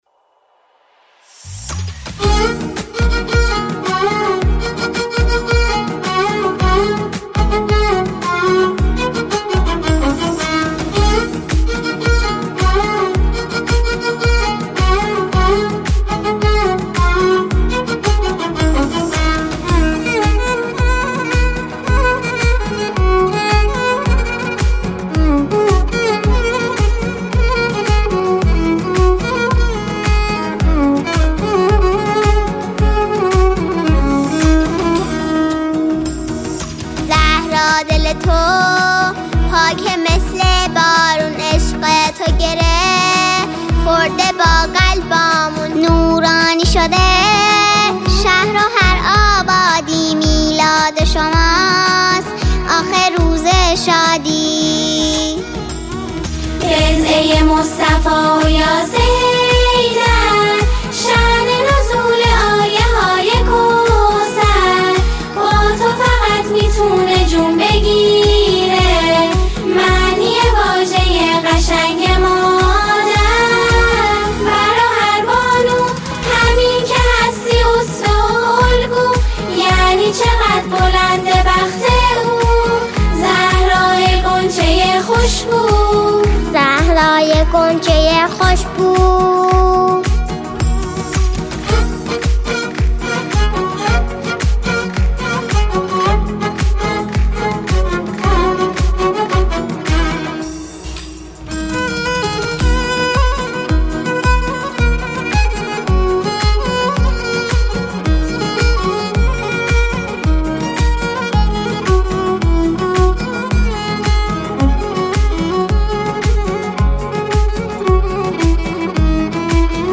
بازخوانی